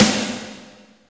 48.05 SNR.wav